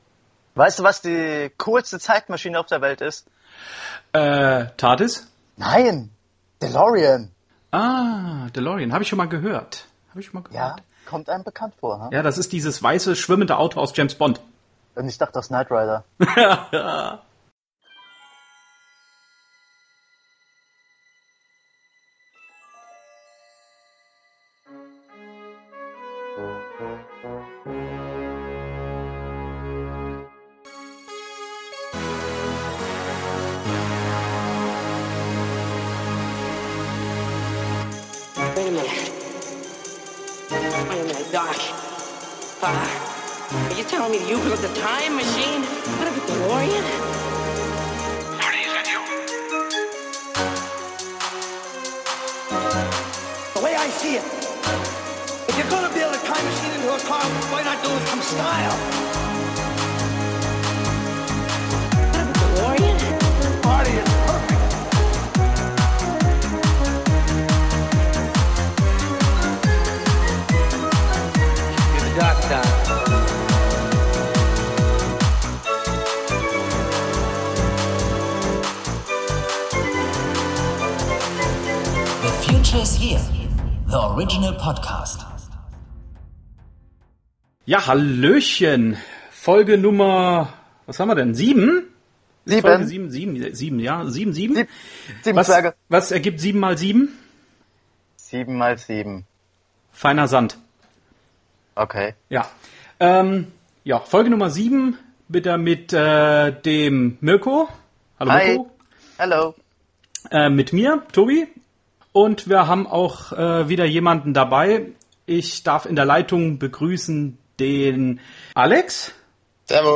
Folge Nummer 7 :-) Dieses mal sprechen wir wieder zu dritt.